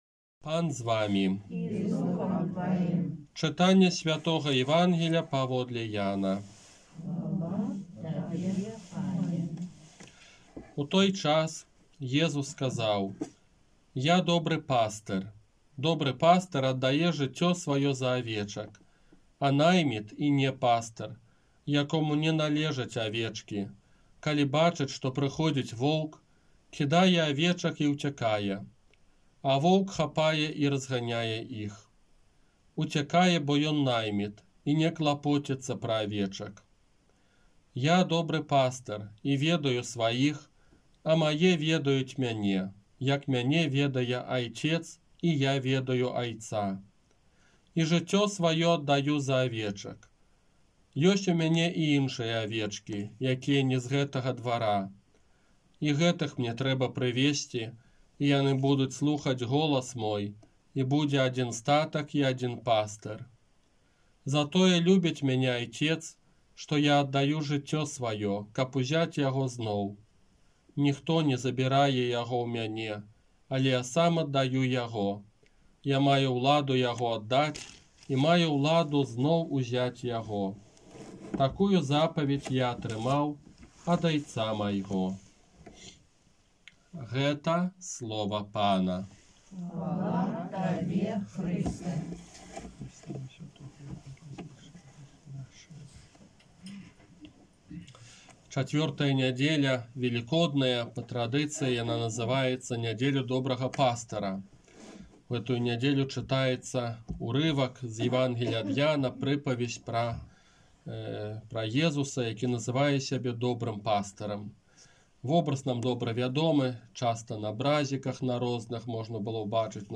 ОРША - ПАРАФІЯ СВЯТОГА ЯЗЭПА
Казанне на чацвёртую Велікодную нядзелю 25 красавіка 2021 года